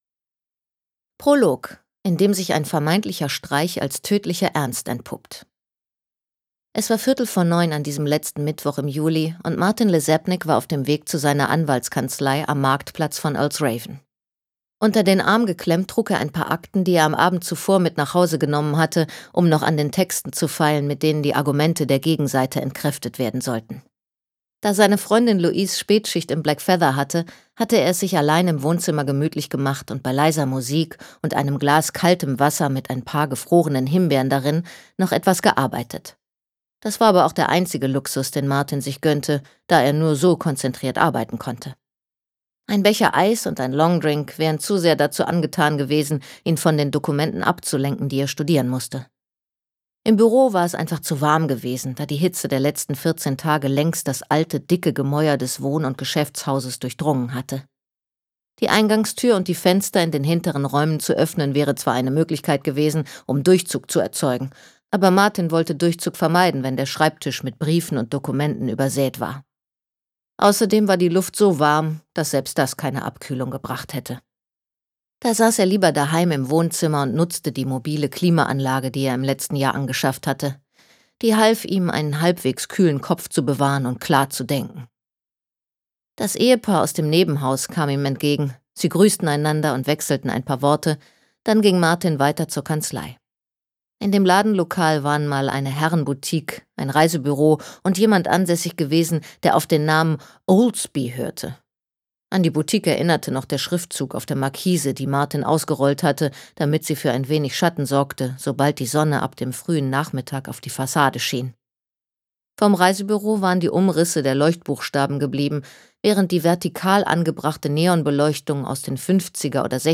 Hörbuch: Tee?